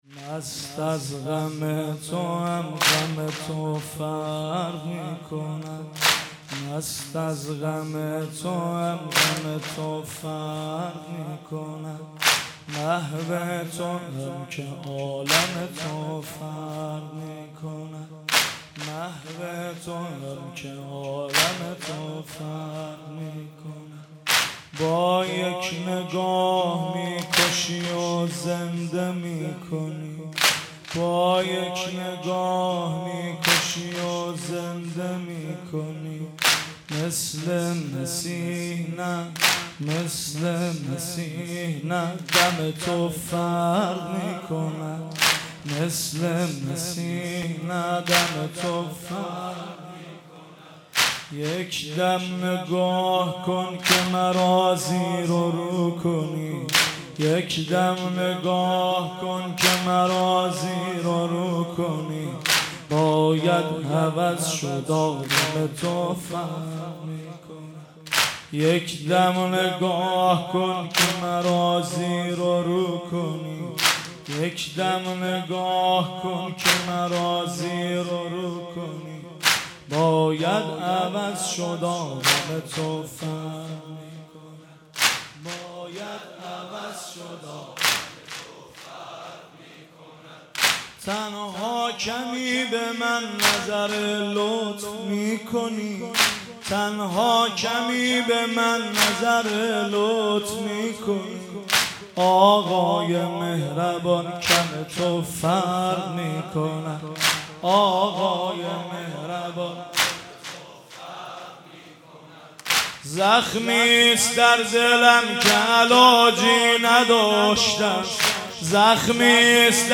مراسم عزاداری دهه اول محرم در حسینیه حاج همت در میدان شهدا
صوت مراسم شب هفتم محرم ۱۴۳۷ دانشگاه امیرکبیر و حسینیه حاج همت ذیلاً می‌آید: